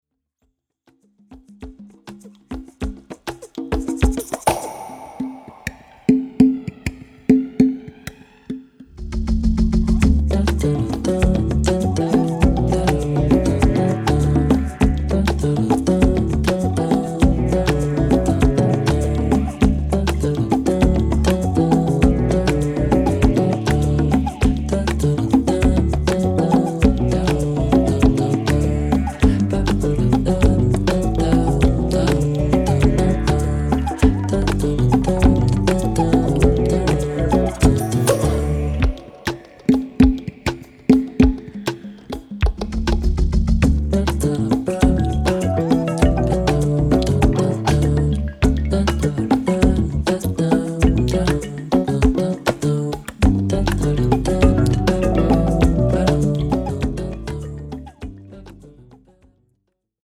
Ambient Pop